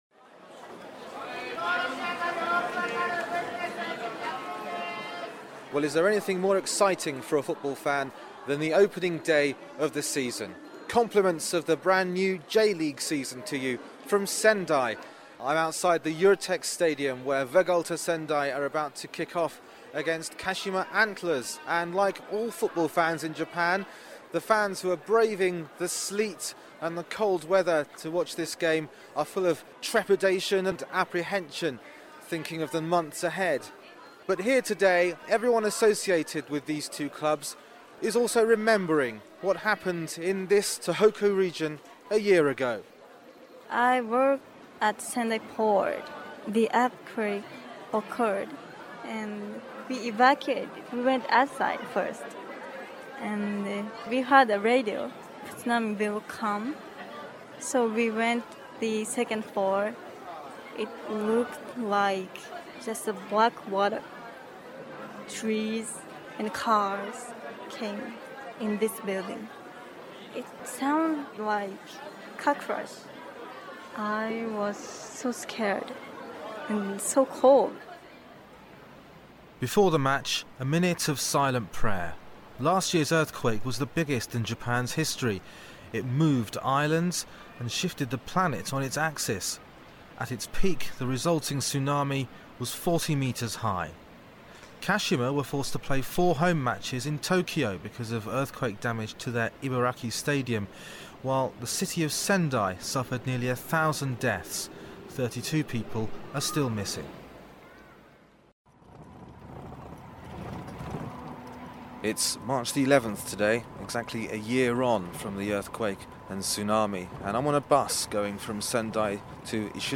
I visit Sendai for Vegalta Sendai's J.League opener vs the tea from the other hugely-affected region of Japan, Kashima Antlers.